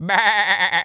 sheep_baa_bleat_high_02.wav